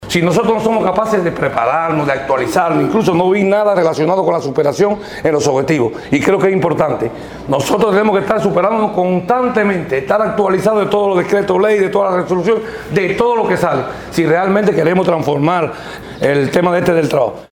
Un análisis profundo sobre la labor del trabajador social en la comunidad y la situación del empleo laboral, signaron los debates en el balance del quehacer durante el 2023 de la Dirección Municipal de Trabajo y Seguridad Social este viernes en Jobabo.